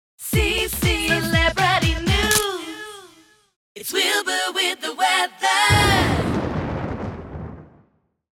montage of a few vocal clips from 2017 Purplebricks (US) Radio campaign